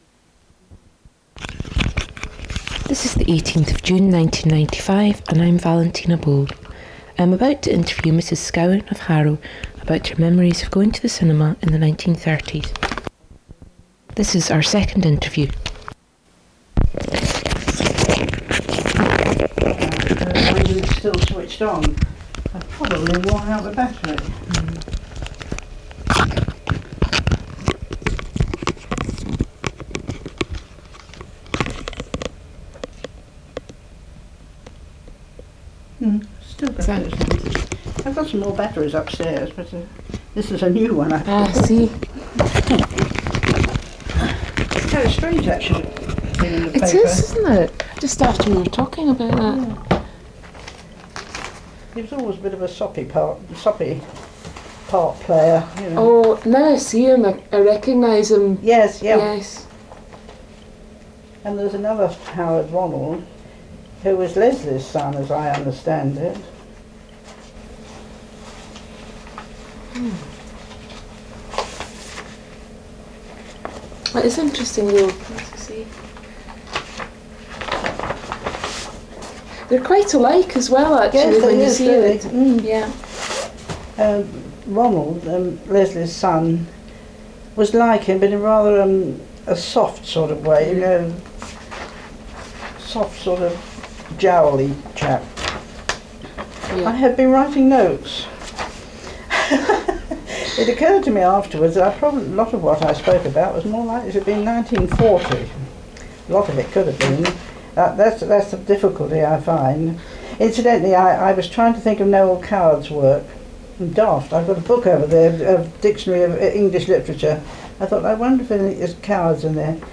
Cinema Memory - 1930s Britain